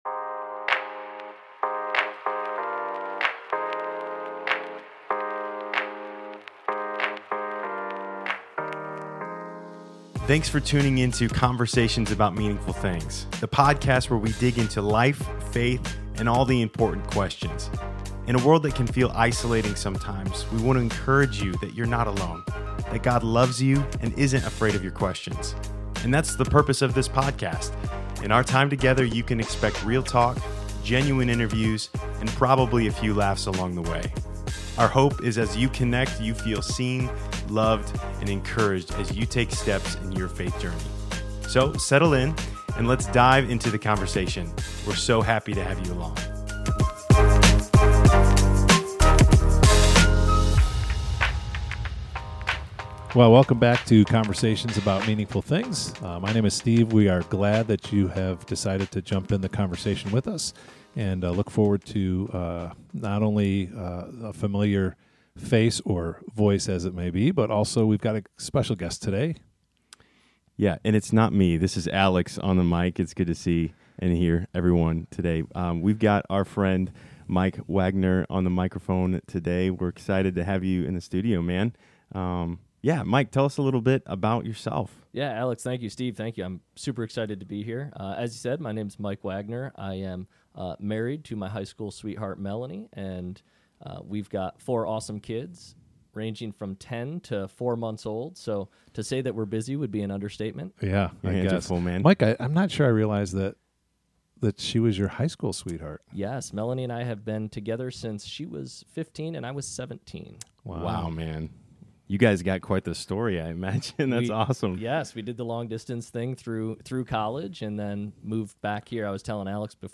have a powerful conversation